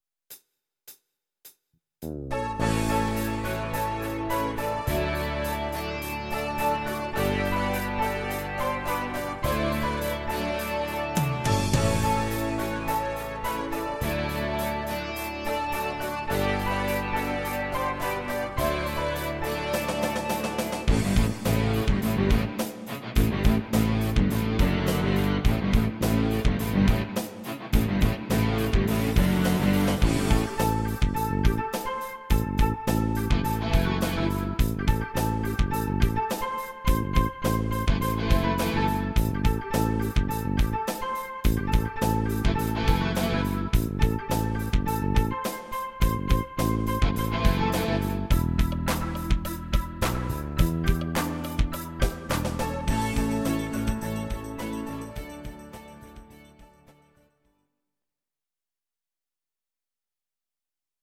Audio Recordings based on Midi-files
Rock, German